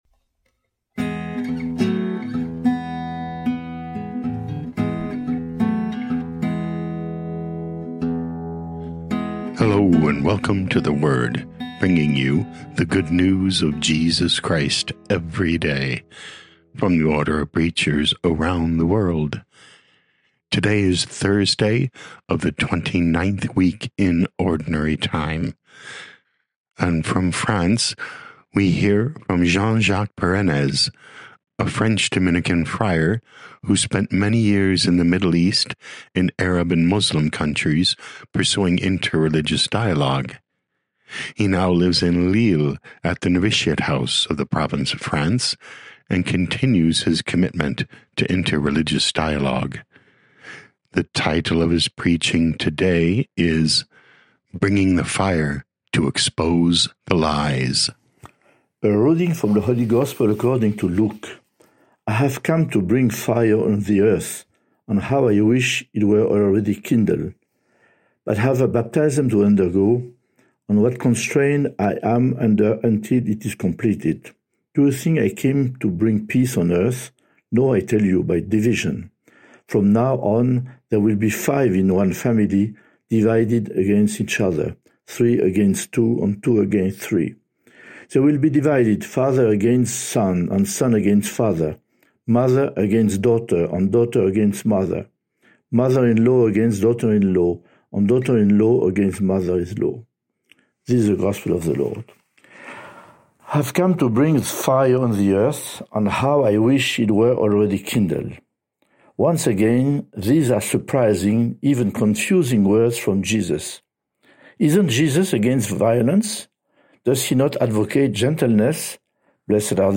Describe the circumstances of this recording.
23 Oct 2025 Bringing the Fire to Expose the Lies Podcast: Play in new window | Download For 23 October 2025, Thursday of week 29 in Ordinary Time, based on Luke 12:49-53, sent in from Lille, France.